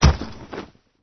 （*：着地音效太重了，而投掷物是很轻的石块
投掷的东西落地.wav